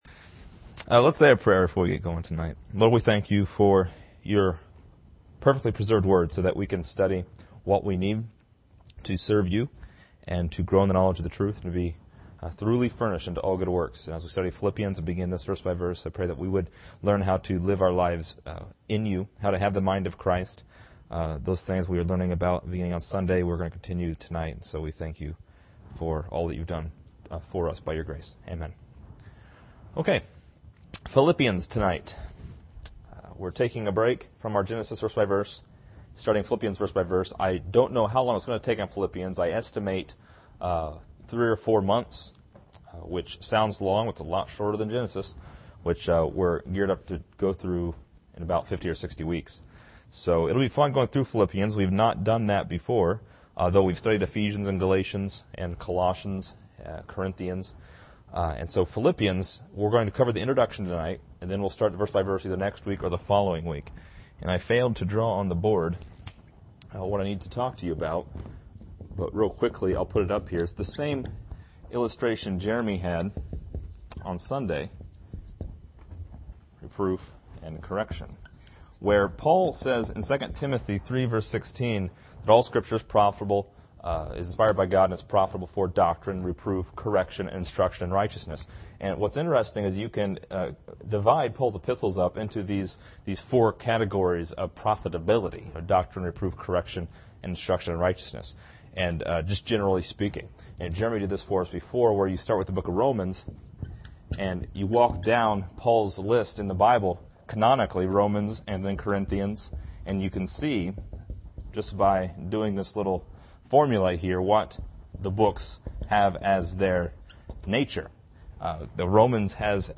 This lesson is part 01 in a verse by verse study through Philippians titled: Introduction.